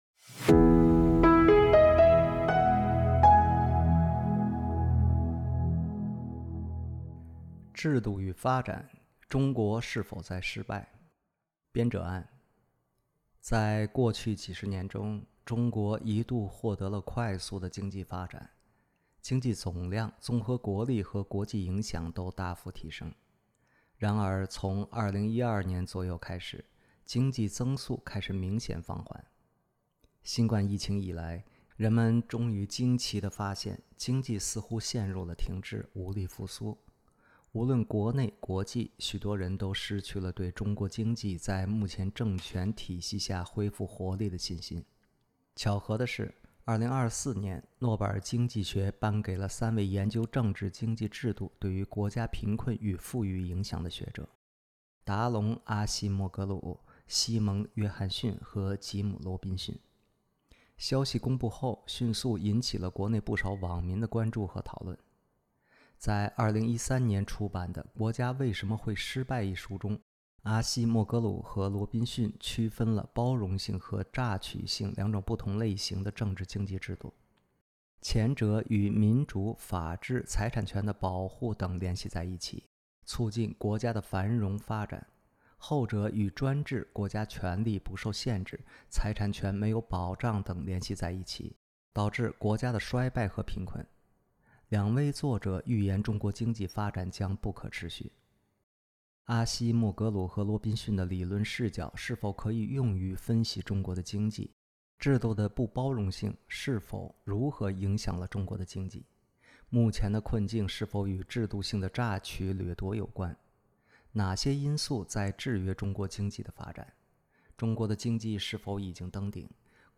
5 位专家与观察人士进行讨论